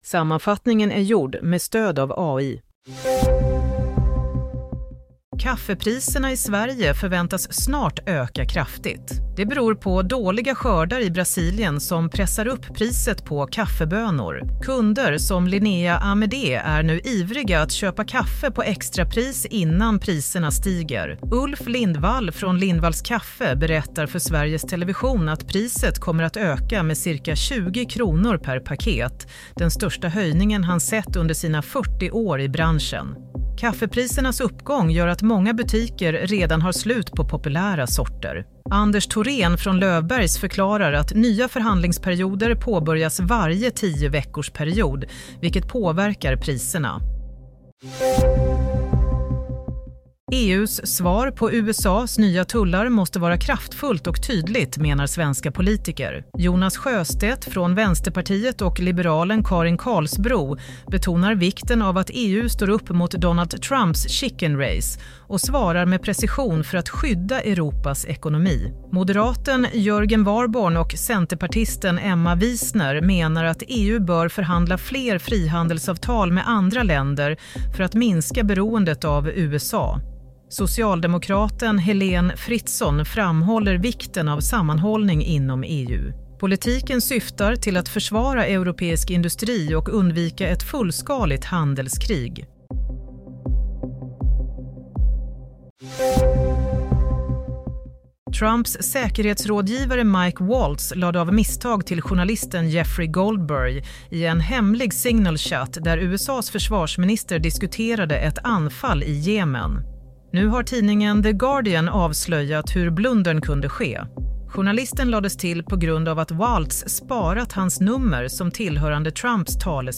Nyhetssammanfattning – 6 april 22:00